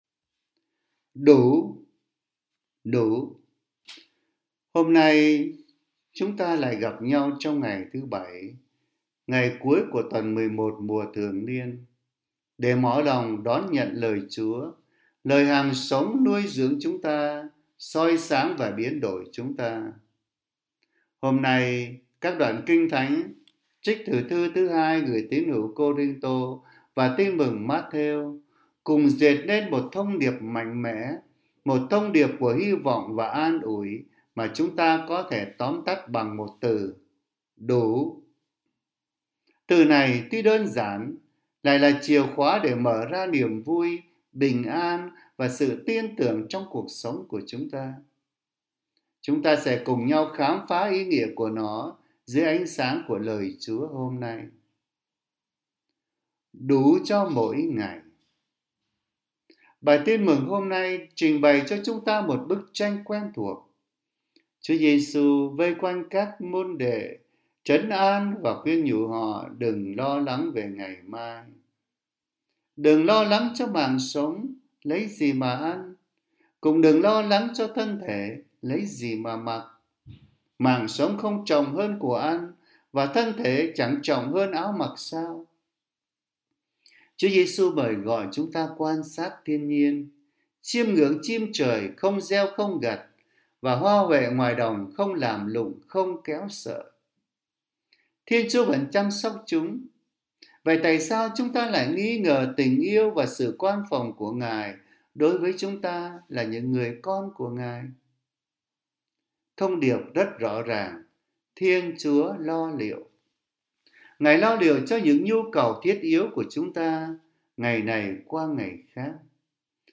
Suy niệm hằng ngày Thứ Bảy, Tuần XI, Mùa Thường Niên: Đủ!!!